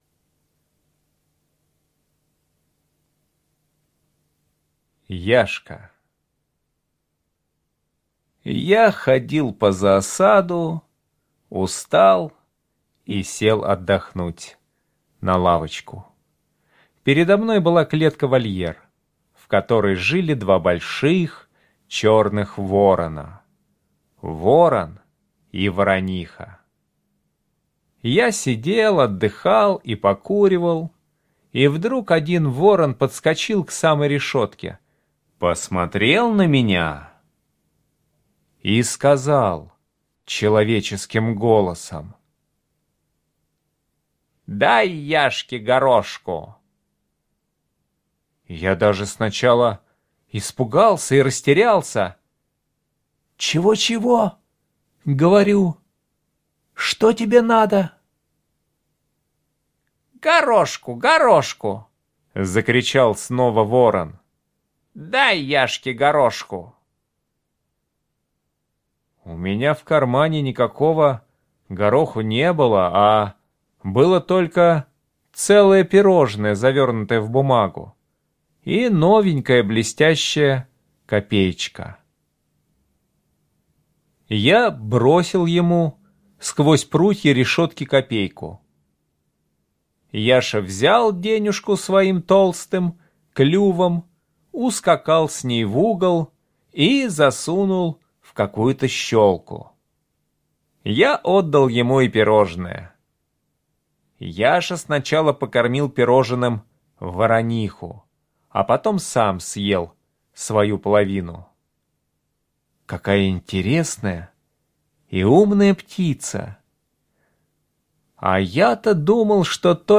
Слушайте Яшка - аудио рассказ Чарушина Е.И. Автор гулял в зоопарке и к нему подлетел ворон и сказал человеческим голосом: — Дай Яше горошку!